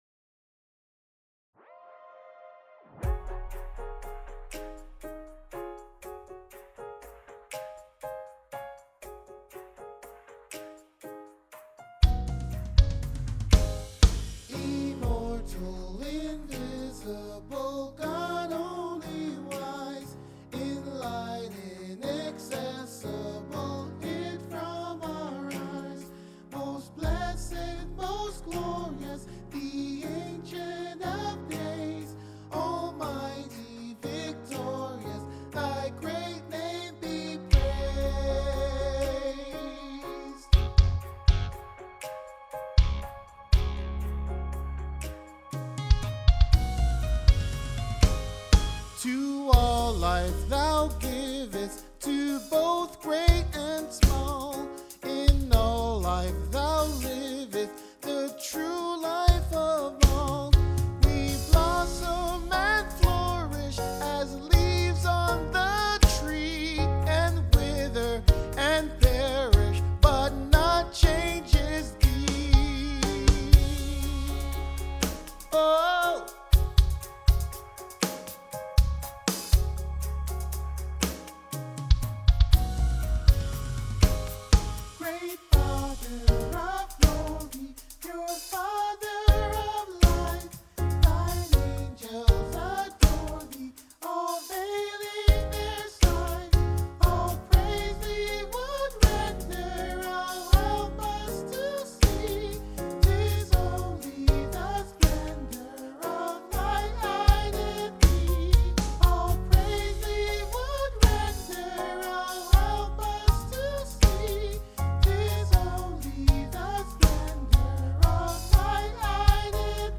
IMMORTAL INVISIBLE GOD : SOPRANO